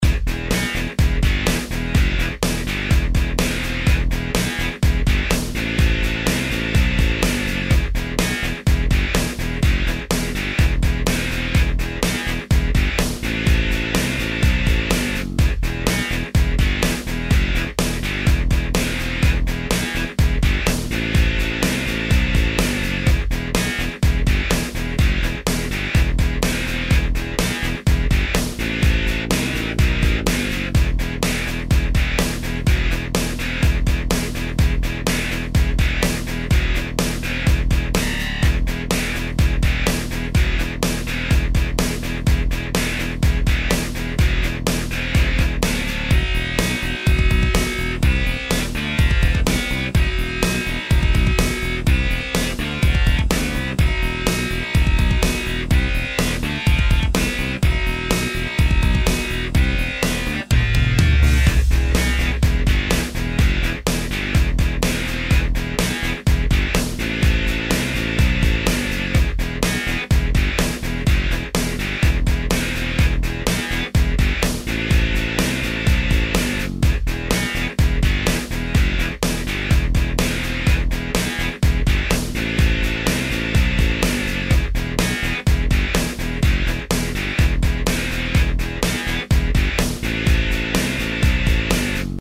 まだ途中だけど初めて打ち込みで作ったハードロック🎵